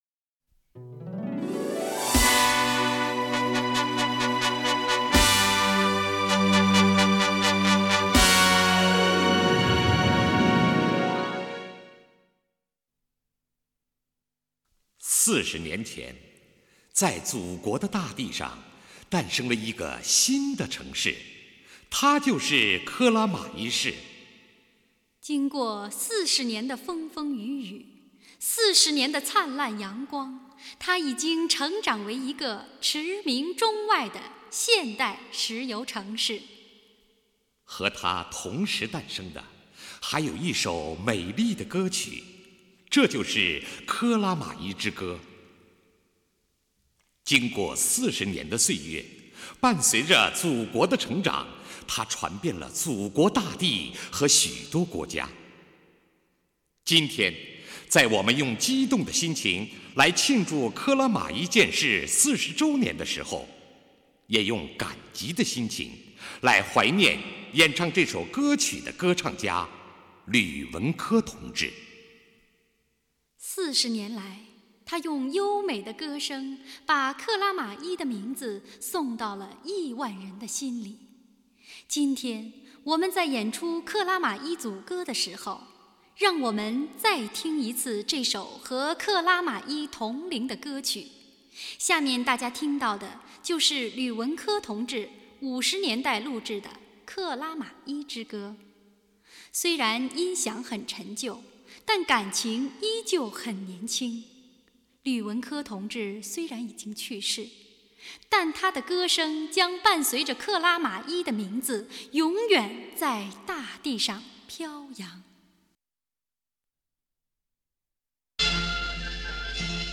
1958年录音